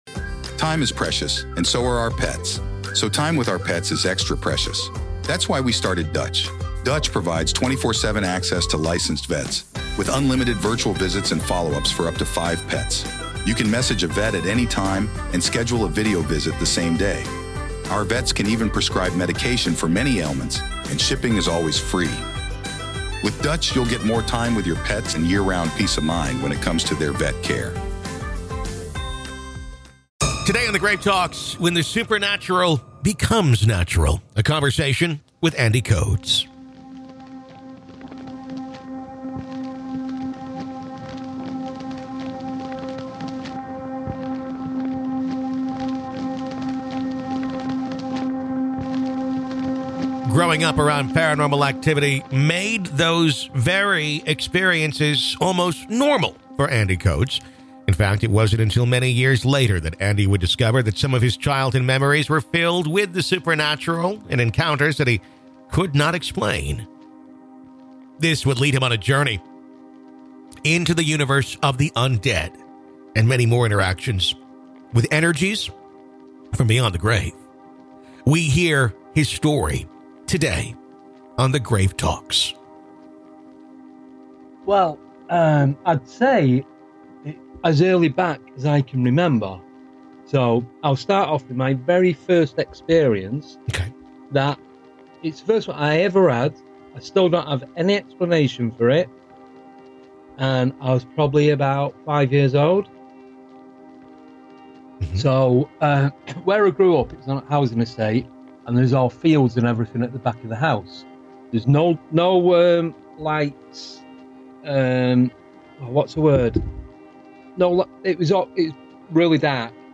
PART 2 - AVAILABLE TO GRAVE KEEPERS ONLY - LISTEN HERE In part two of our interview, available only to Grave Keepers , we discuss: Why do spirits seem to inhabit areas that they may have never spent much time in life?